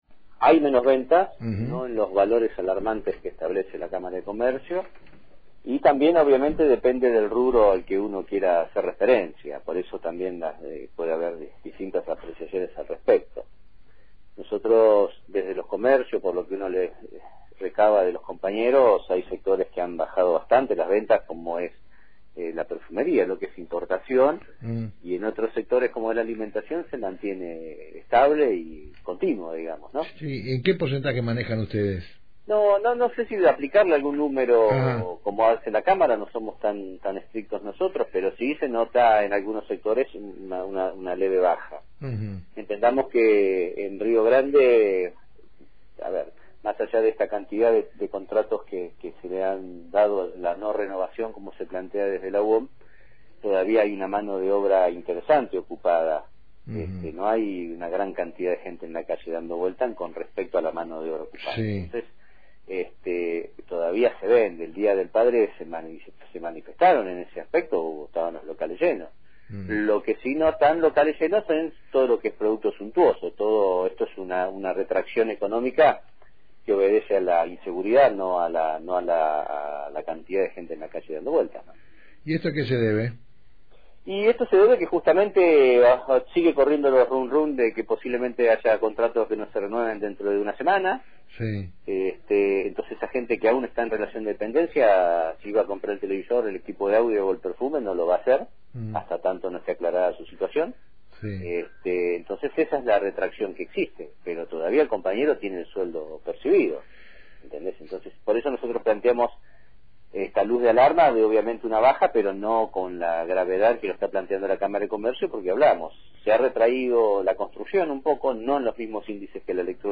Publicado enLocales